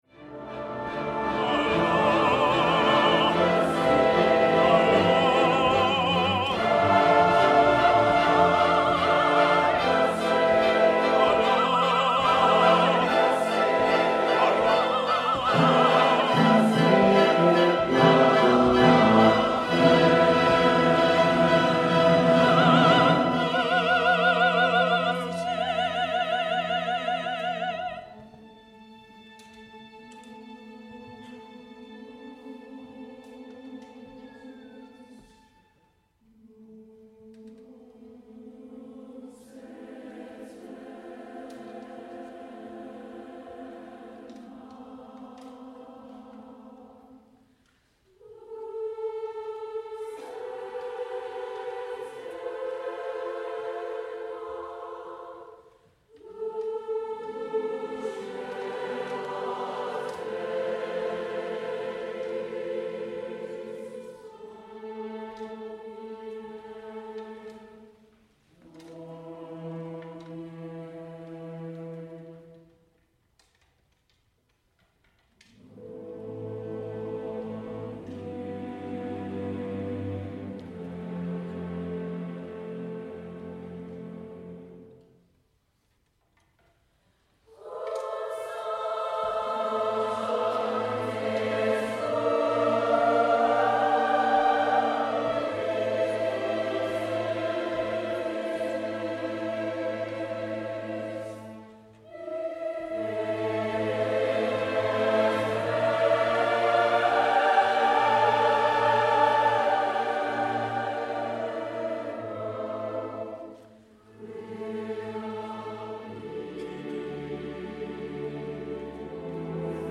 Accompaniment:      Organ
Music Category:      Choral